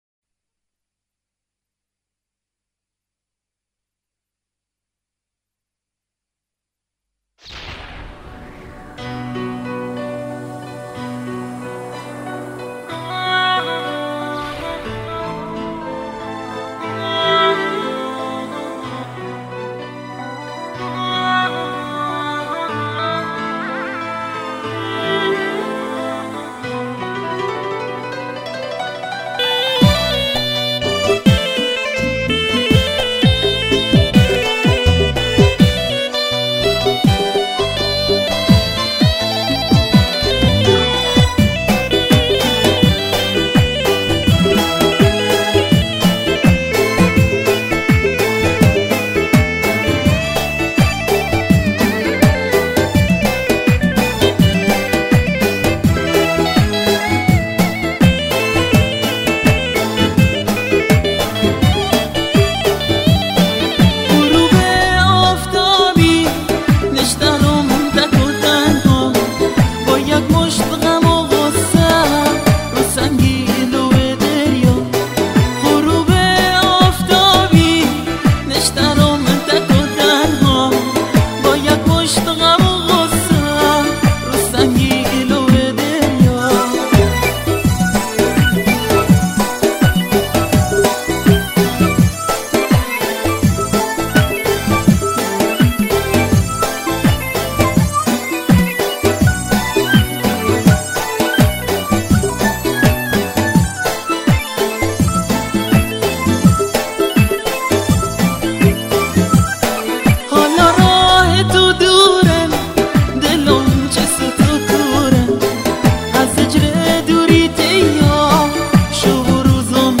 تمام تراک‌های این آلبوم به سبک و گویش بندری است.